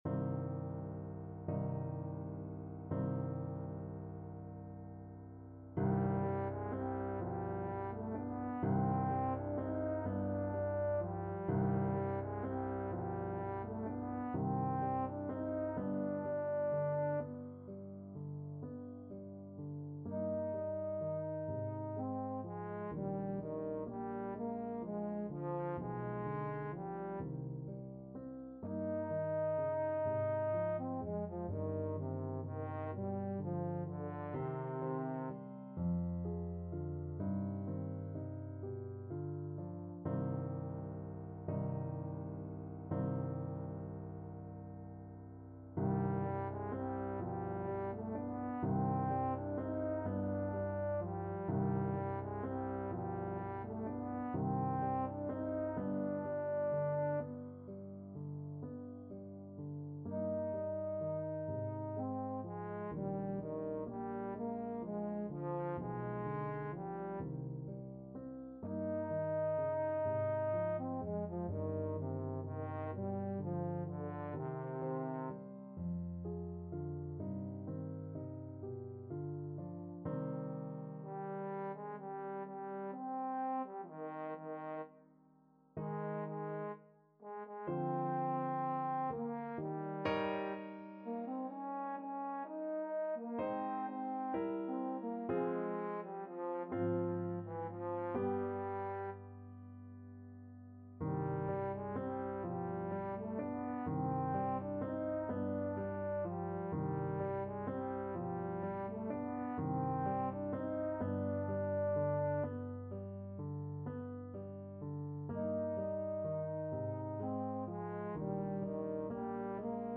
Trombone
C minor (Sounding Pitch) (View more C minor Music for Trombone )
6/8 (View more 6/8 Music)
= 42 Andante con moto (View more music marked Andante con moto)
Classical (View more Classical Trombone Music)